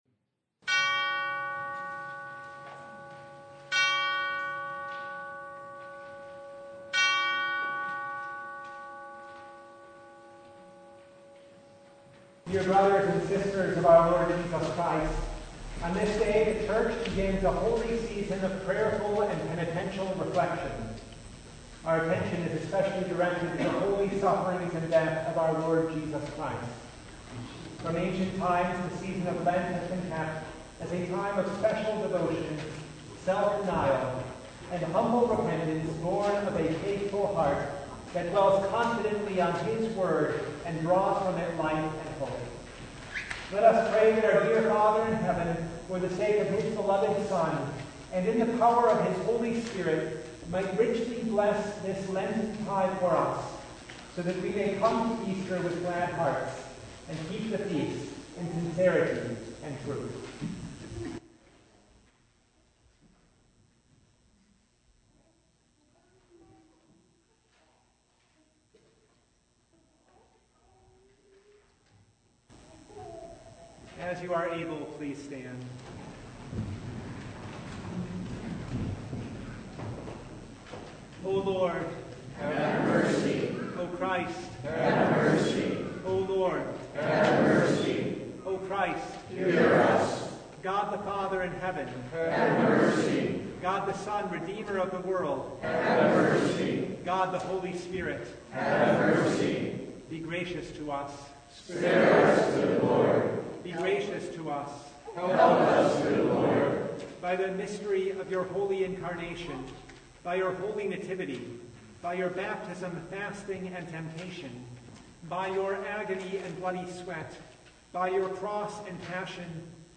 Ash Wednesday Noon Service (2025)